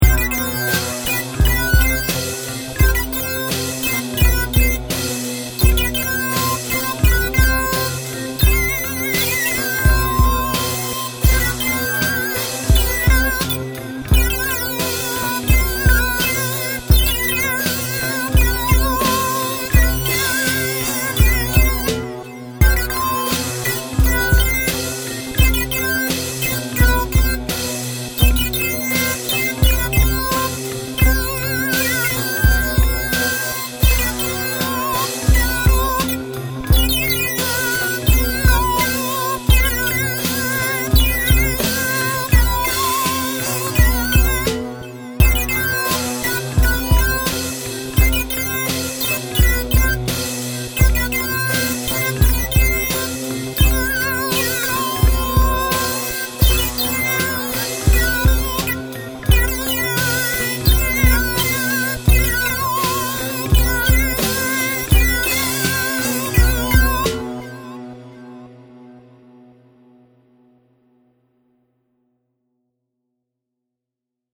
5 minute beat on the new MacBook Air
Filed under: Uncategorized Instrumental | Comments (1)